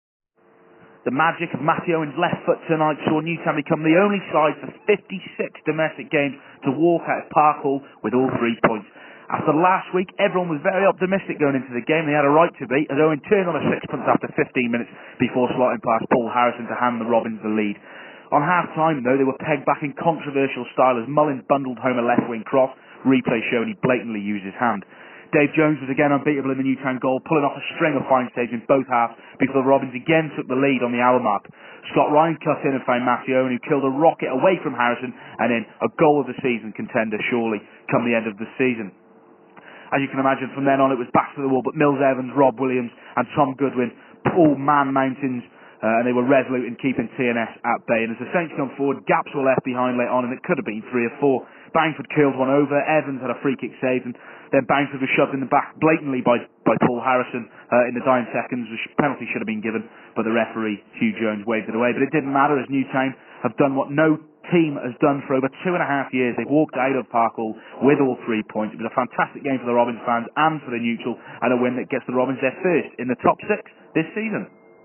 AUDIO REPORT - TNS 1-2 Robins